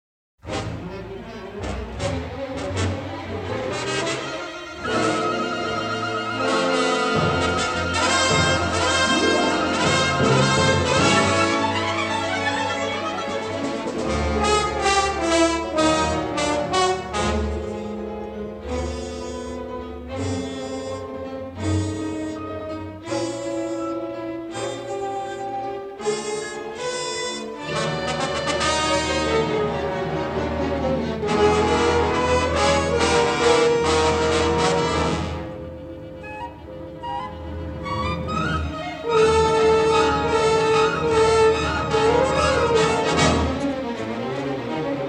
in stereo sound